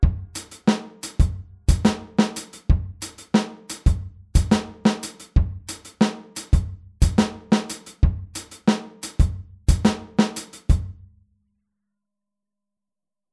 Nach dem 4tel-Offbeat setzen wir ein Echo hinzu
Hier spielen wir den Offbeat mit der rechten Hand wieder auf dem HiHat.